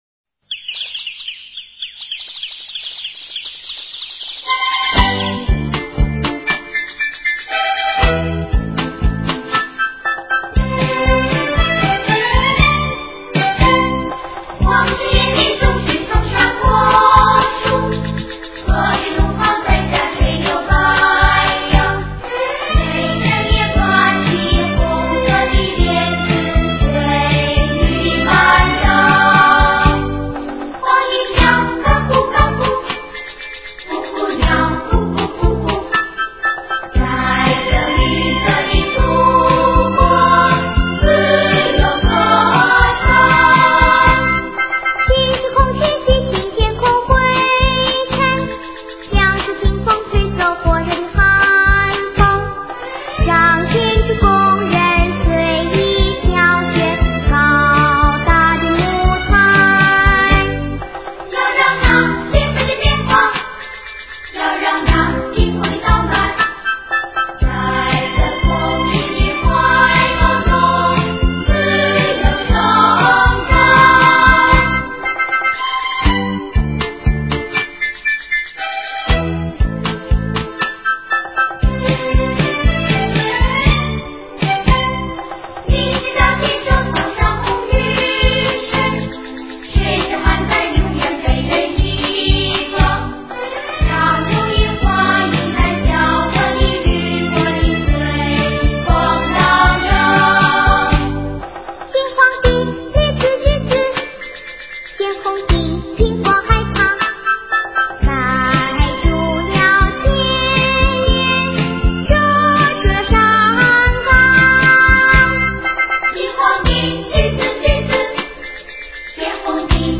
Canciones Infantiles chinas